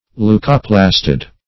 leucoplastid.mp3